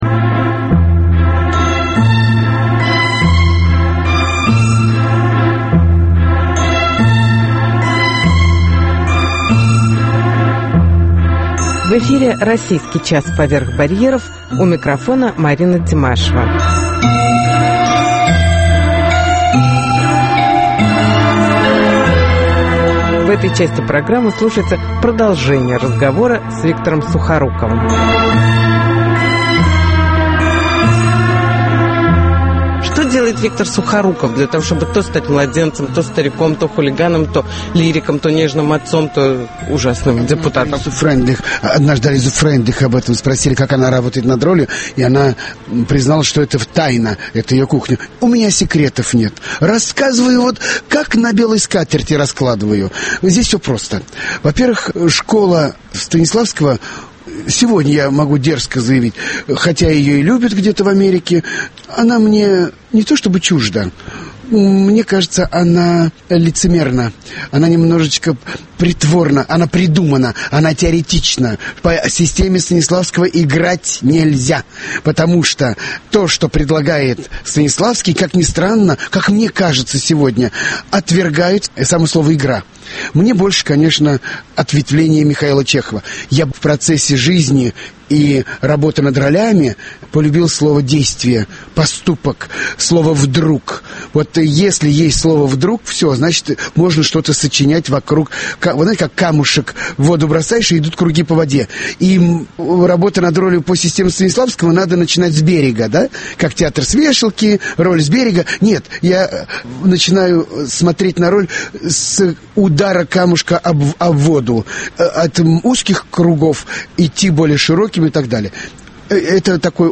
Интервью с Виктором Сухоруковым (часть вторая)